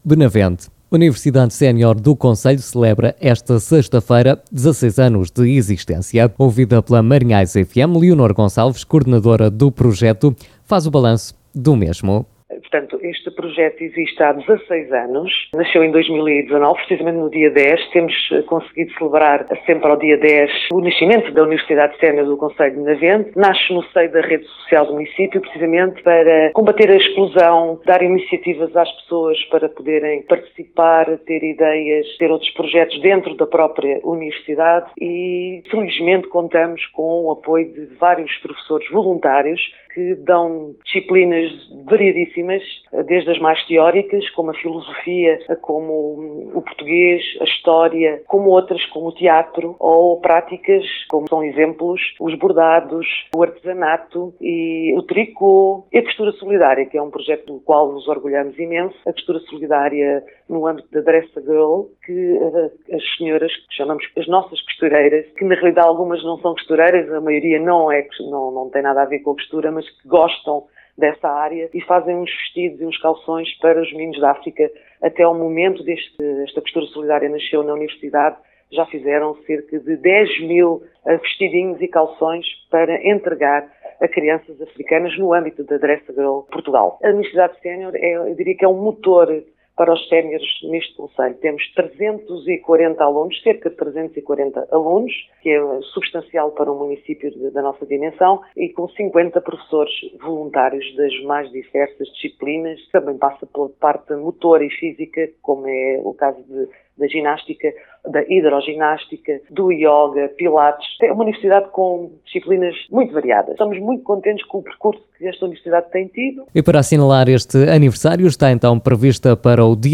Ouvida pela Rádio Marinhais